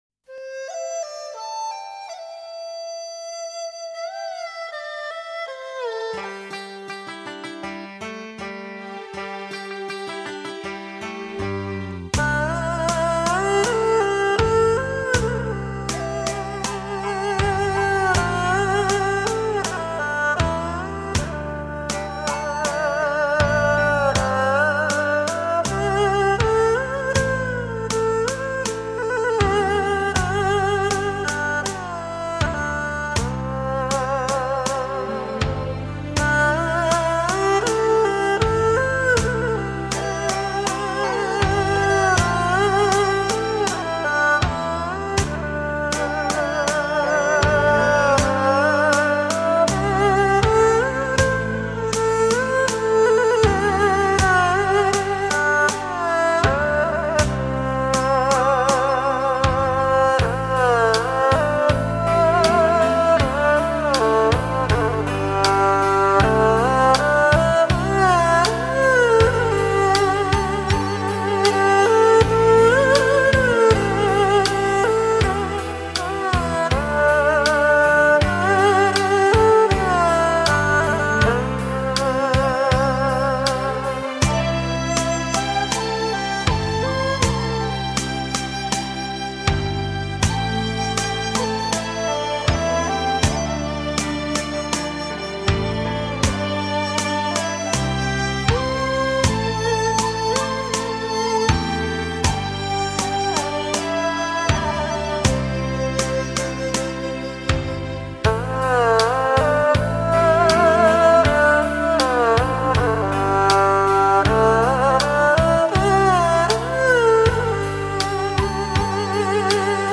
最美妙的旋律 柔情蜜意的二胡 让经典伴你一路同行